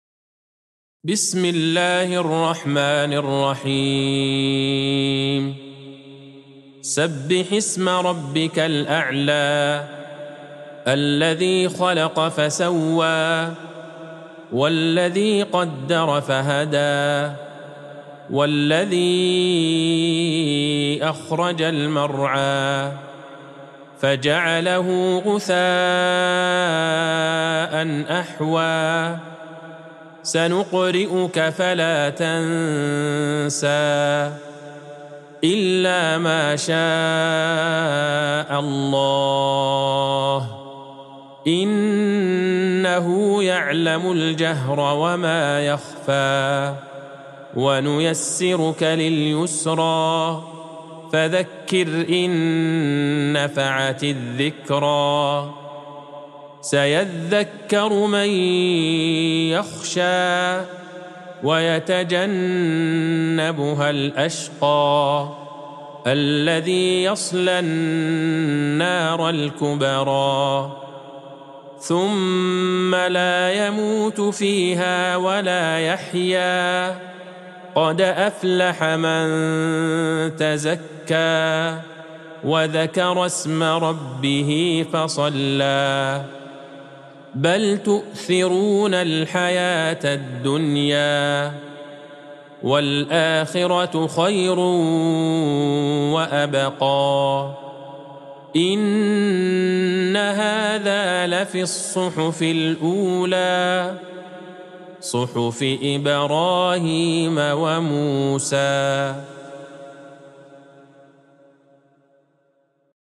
سورة الأعلى Surat Al-Ala | مصحف المقارئ القرآنية > الختمة المرتلة ( مصحف المقارئ القرآنية) للشيخ عبدالله البعيجان > المصحف - تلاوات الحرمين